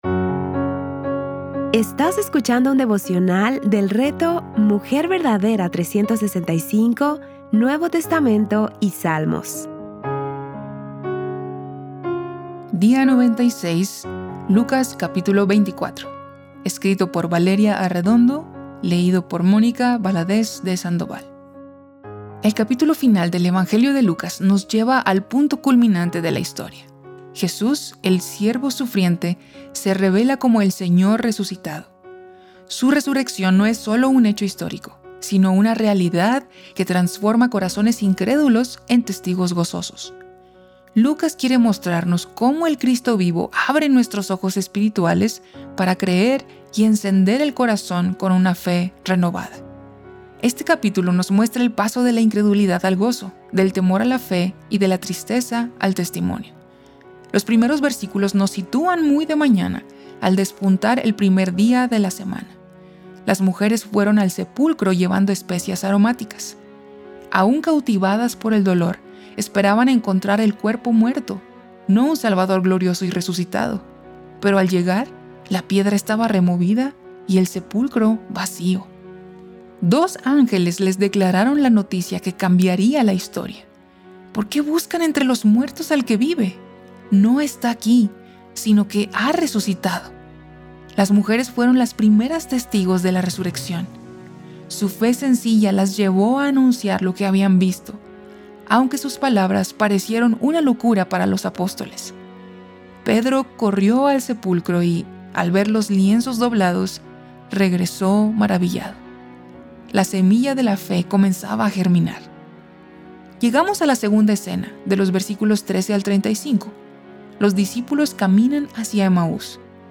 Series:  Lucas y Salmos | Temas: Lectura Bíblica